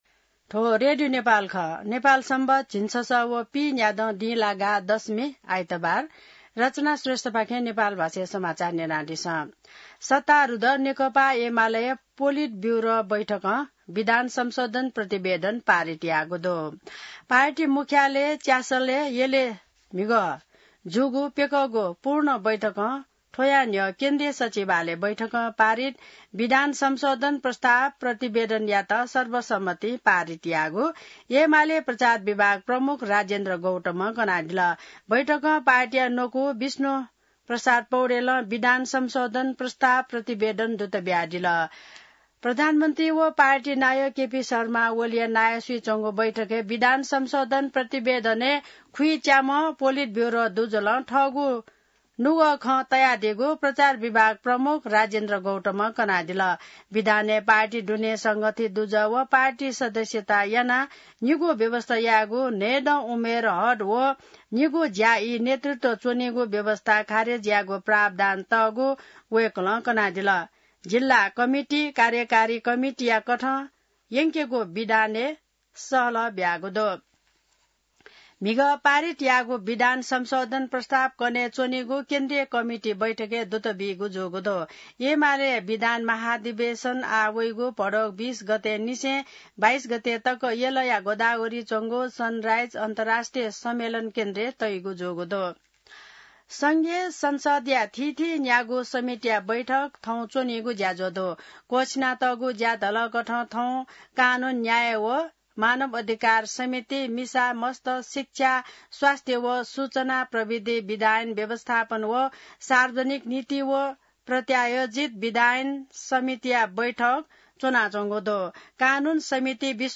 नेपाल भाषामा समाचार : ४ साउन , २०८२